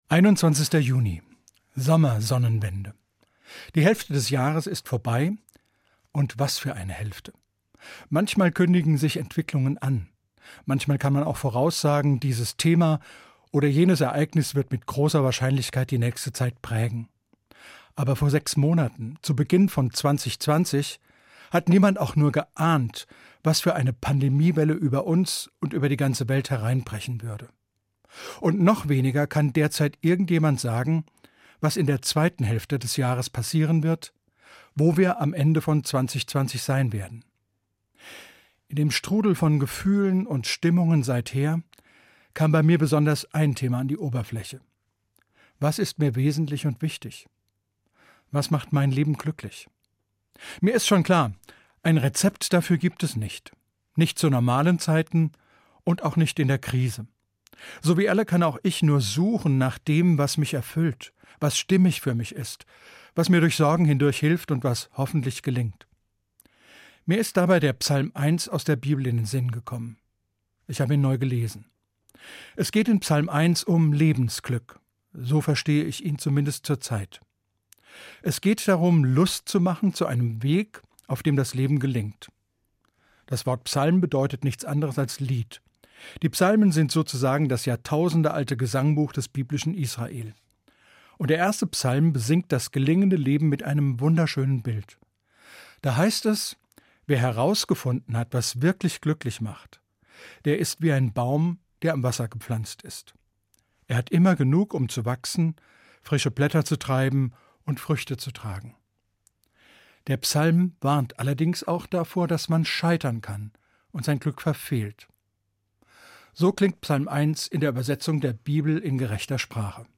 hr2 MORGENFEIER